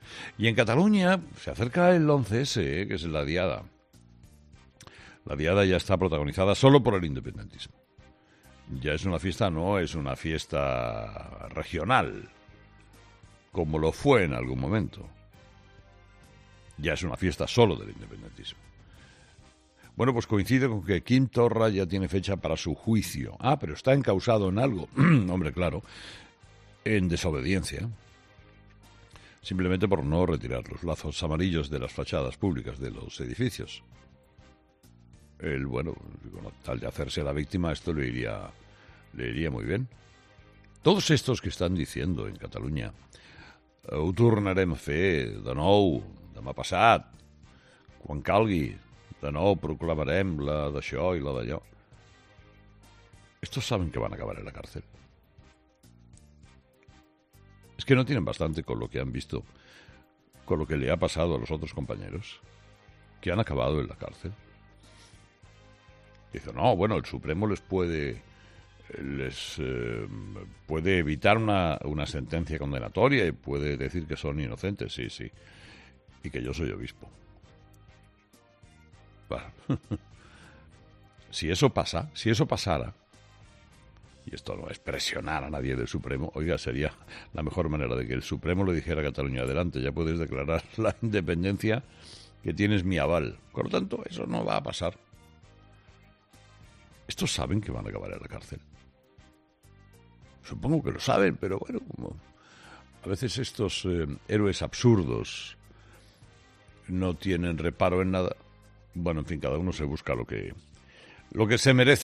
En su monólogo de este martes, Carlos Herrera ha lanzado una clara advertencia al independentismo.